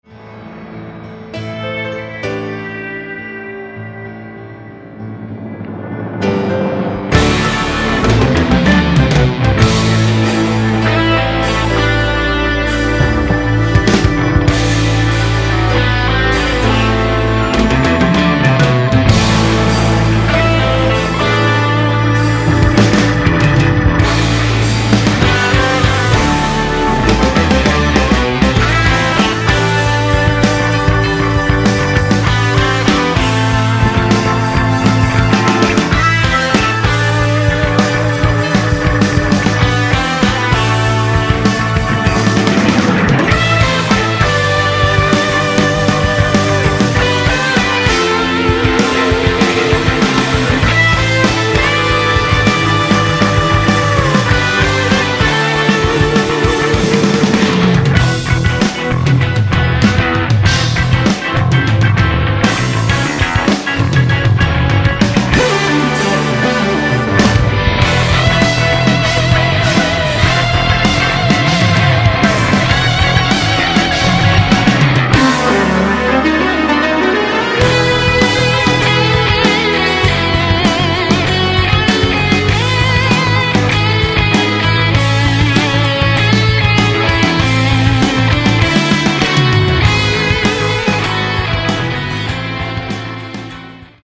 стать очередным арт-рок проектом
клавишные, вокал
гитара
бас
ударные